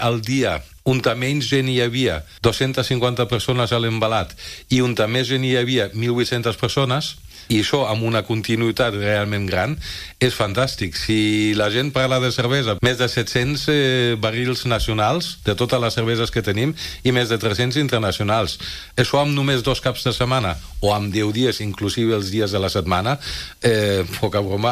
en una entrevista al magazine A l’FM i + de Ràdio Calella TV
Aquestes són les xifres d’assistència que ha donat al programa matinal de Ràdio Calella TV.